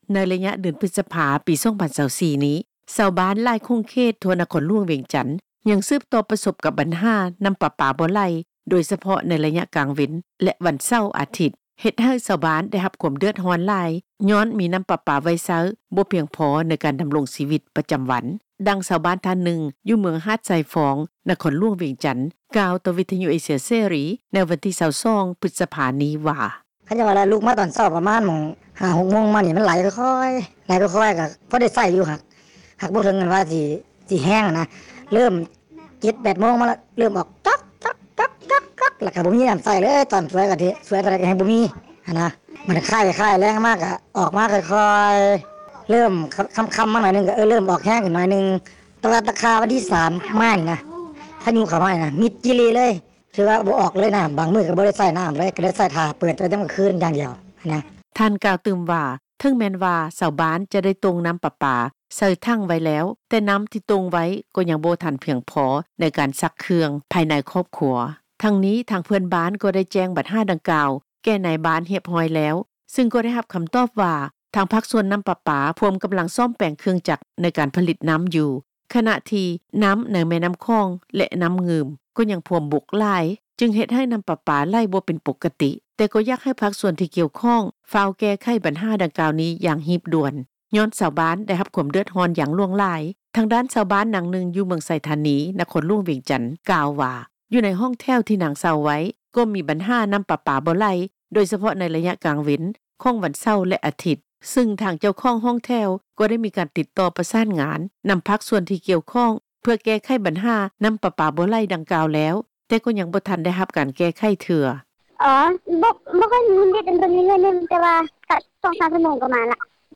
ດັ່ງທີ່ ຊາວບ້ານ ທ່ານນຶ່ງ ຢູ່ເມືອງຫາດຊາຍຟອງ ນະຄອນຫຼວງວຽງຈັນ ກ່າວຕໍ່ວິທຍຸເອເຊັຽເສຣີ ໃນວັນທີ 22 ພຶດສະພາ ນີ້ວ່າ.